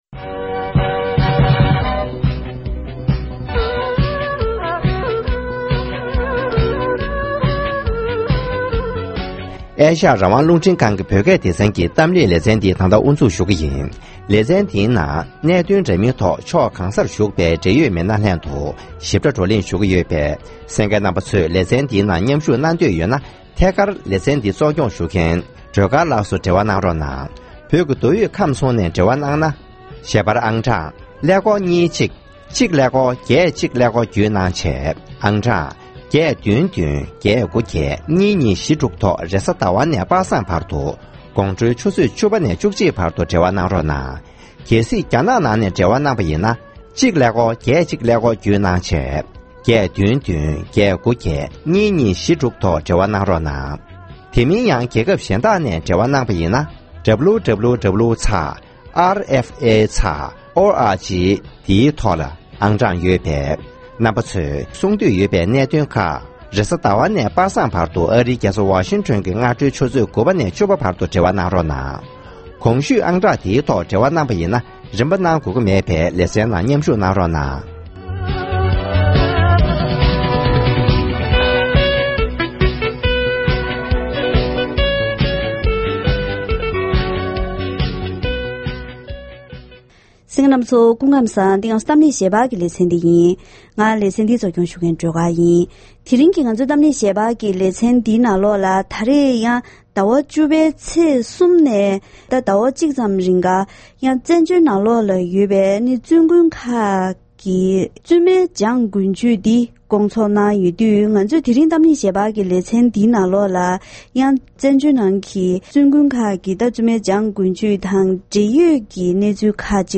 ༄༅། །དེ་རིང་གི་གཏམ་གླེང་ཞལ་པར་ལེ་ཚན་ནང་བཙན་བྱོལ་ལ་ཡོད་པའི་བཙུན་དགོན་ཁག་ཅིག་མཉམ་ཞུགས་ཐོག་བཙུན་མའི་འཇང་དགུན་ཆོས་ཐེངས་༢༡ཚོགས་ཤིང་། མཇུག་སྒྲིལ་མཚམས་ལ་སྤྱི་ནོར་༸གོང་ས་༸སྐྱབས་མགོན་ཆེན་པོ་མཆོག་ནས་བཙུན་མ་ཚོར་བླང་དོར་བཀའ་སློབ་གནང་བ་སོགས་ཞིབ་ཕྲའི་གནས་ཚུལ་ཐོག་འབྲེལ་ཡོད་དང་བཀའ་མོལ་ཞུས་པ་ཞིག་གསན་རོགས་གནང་གནང་།